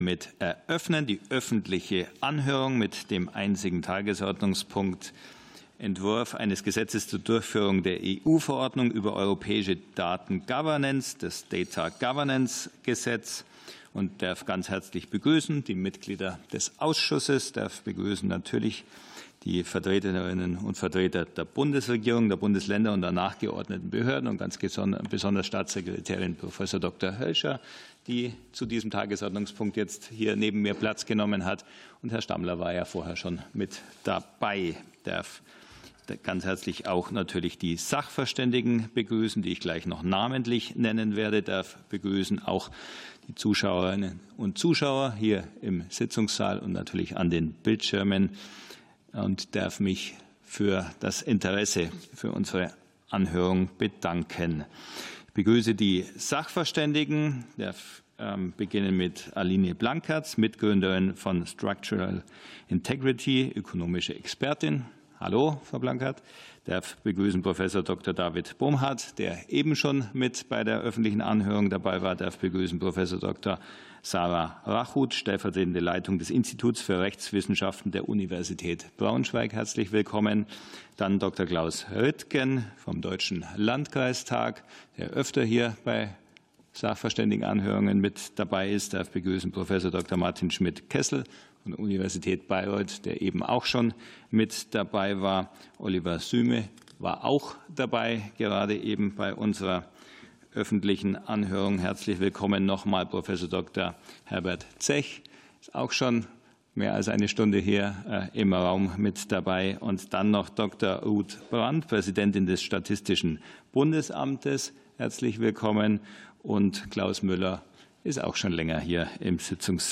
Anhörung zum Daten-Governance-Gesetz ~ Ausschusssitzungen - Audio Podcasts Podcast